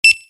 beep.ogg